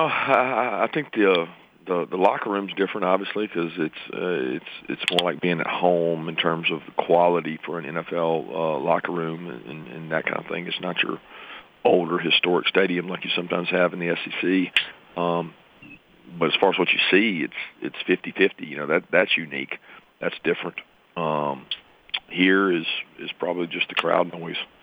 Georgia football head coach Kirby Smart talks preparation for the Bulldogs ahead of the anticipated game against the Florida Gators.
Smart-on-Jax-game.wav